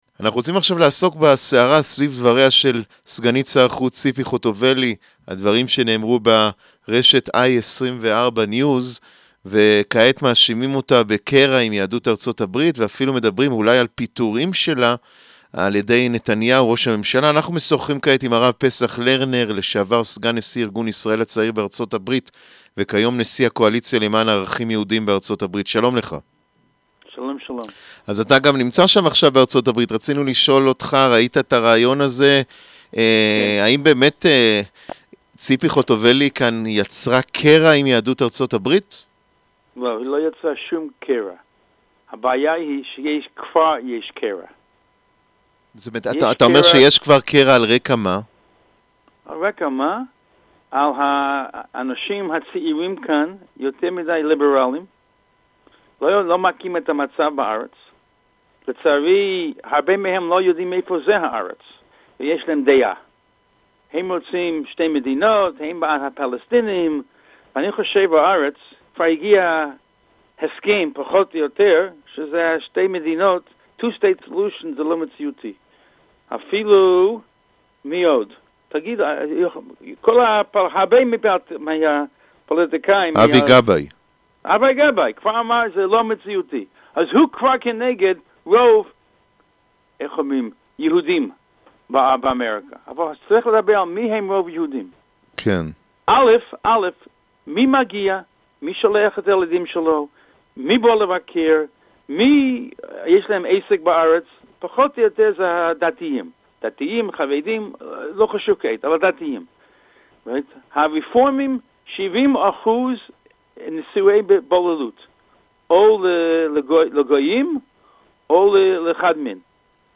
В интервью корреспонденту 7 канала